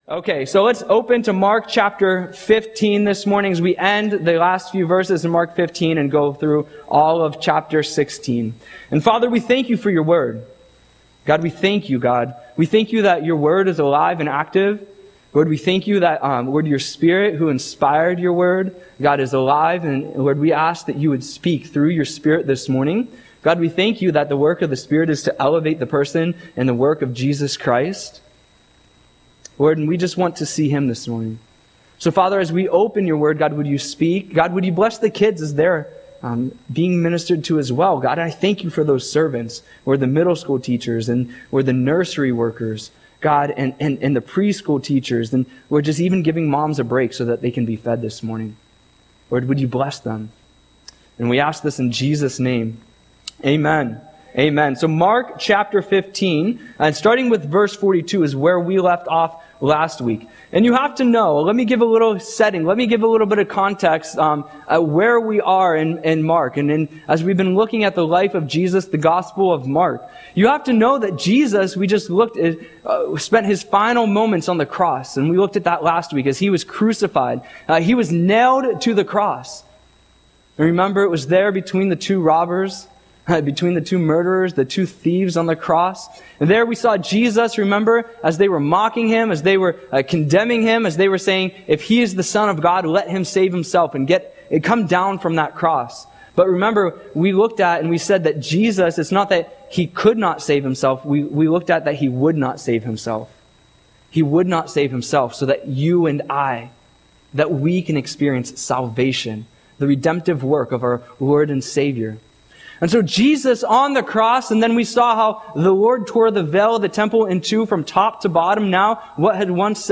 Audio Sermon - May 11, 2025